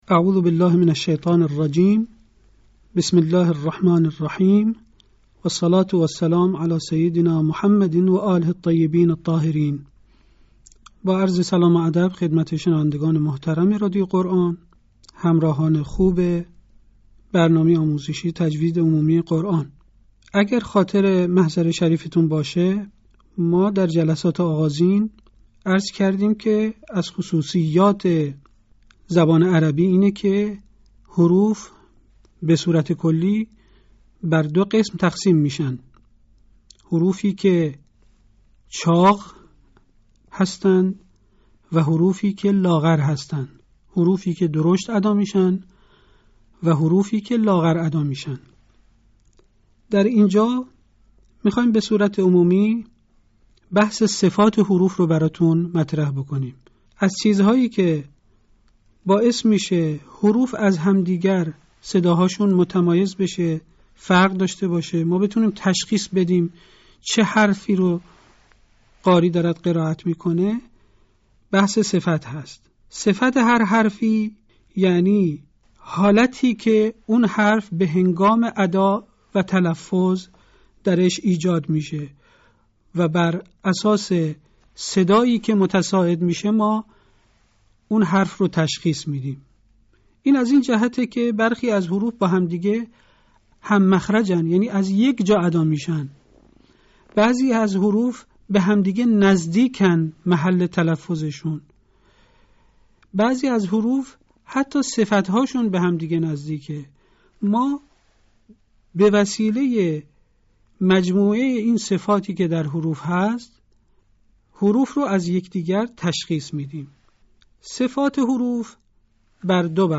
آموزش قرآن